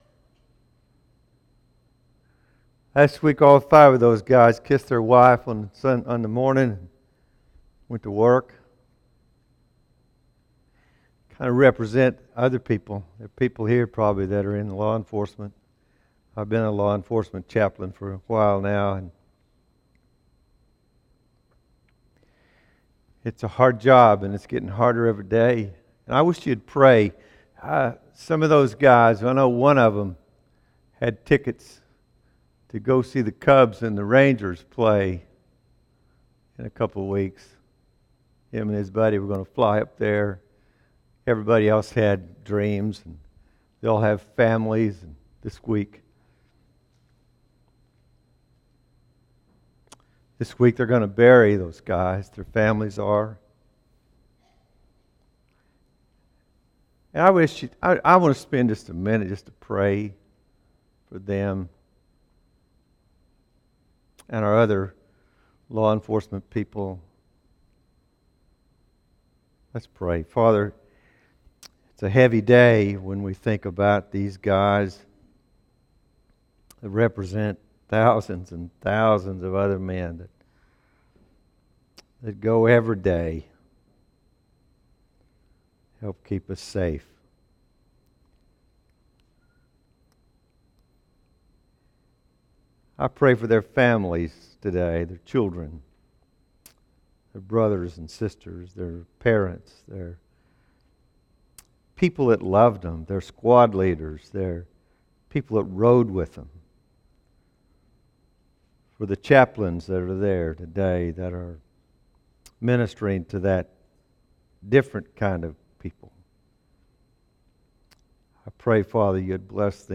Sunday Sermon July 10